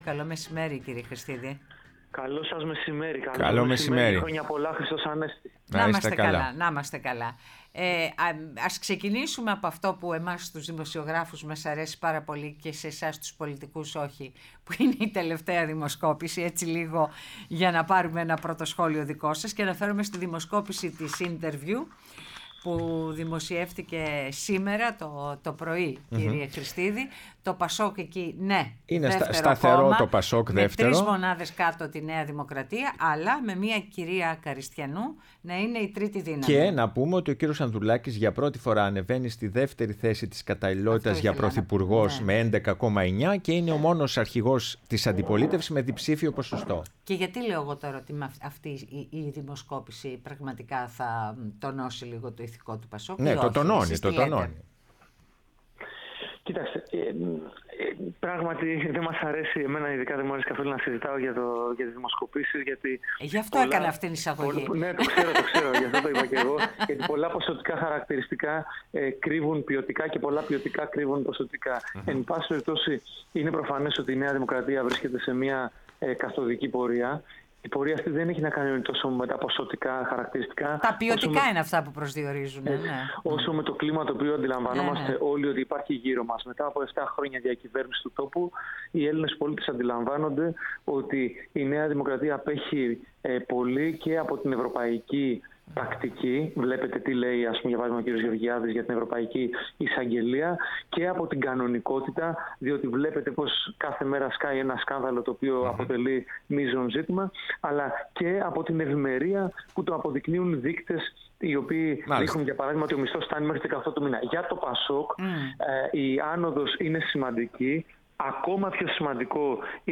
Ο Παύλος Χρηστίδης, βουλευτής ΠΑΣΟΚ-ΚΙΝΑΛ, μίλησε στην εκπομπή «Ναι, μεν Αλλά»